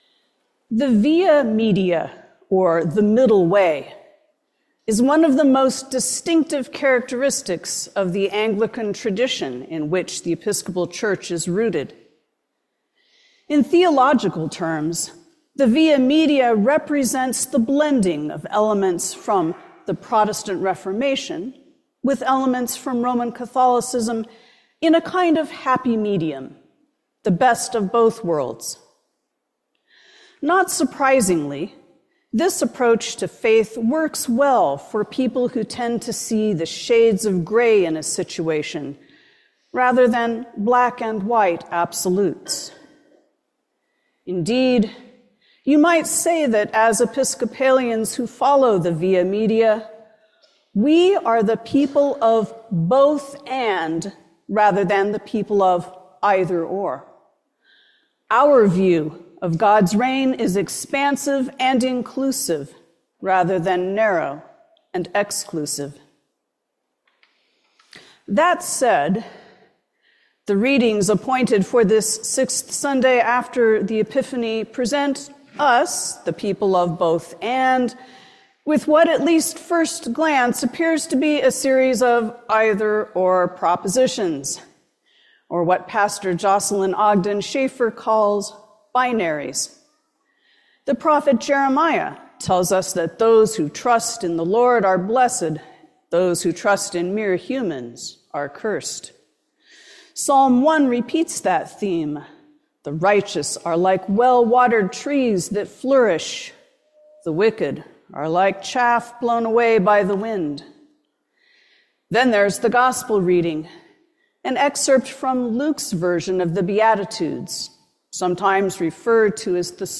Sermon on February 16, 2025